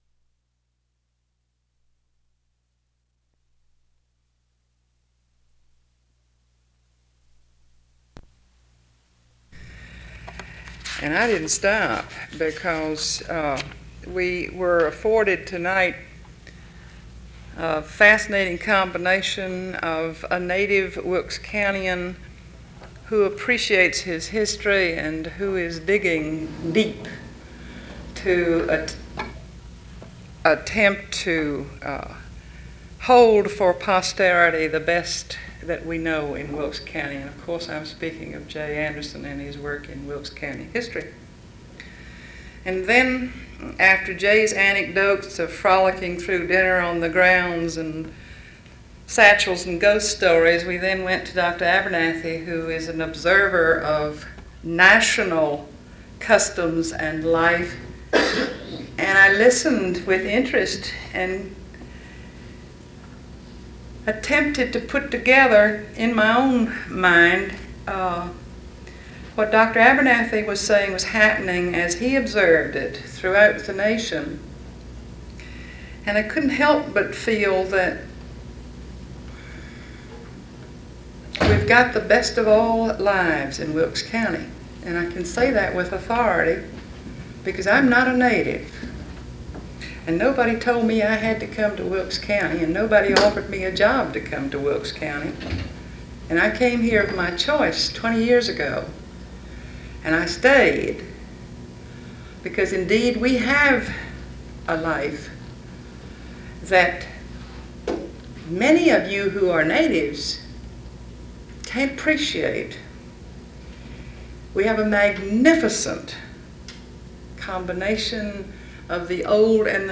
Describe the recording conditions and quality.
A forum focused on the history of Wilkes County, including a lecture and a discussion. This forum was given at East Wilkes High School.